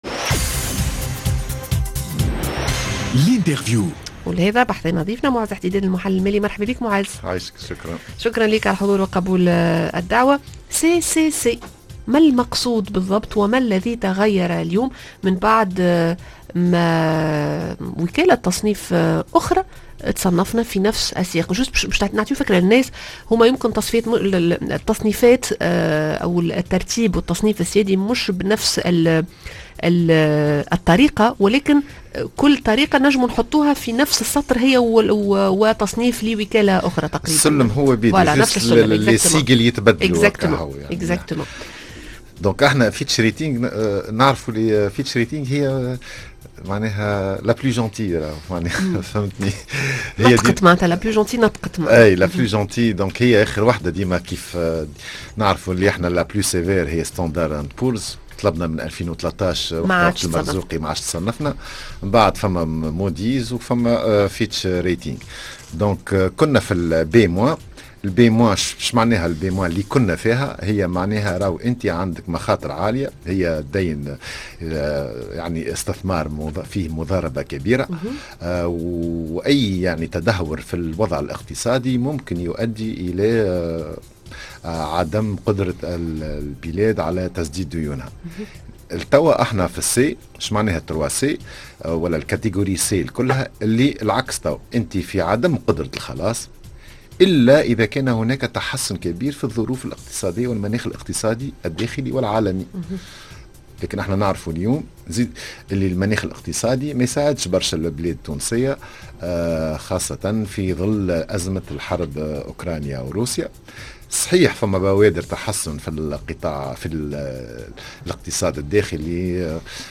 L'interview: و توّا بعد التخفيض في الترقيم السيادي لبلادناشنوّة باش يكون مآل المفاوضات مع صندوق النقد الدولي ؟